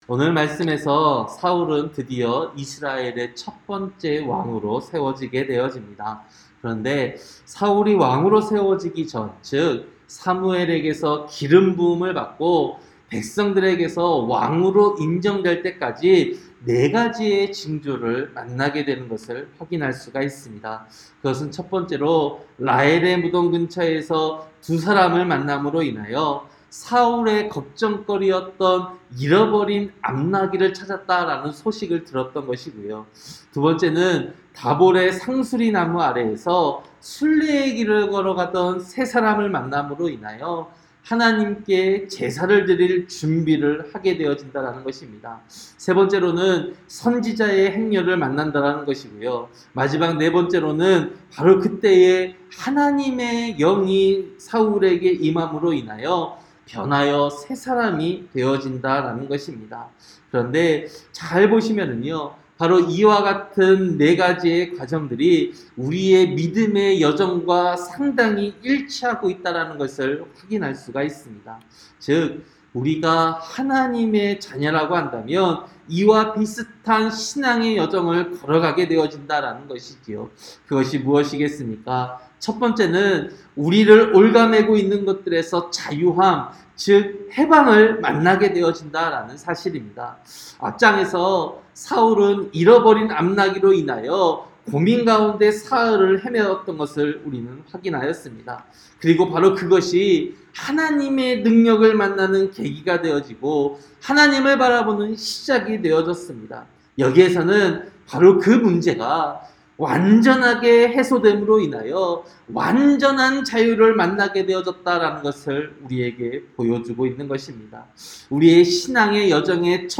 새벽설교-사무엘상 10장